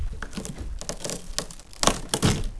Effet sonore - wav Froisser mp3
Froisser
Bruit de quelque chose froissé puis jeté.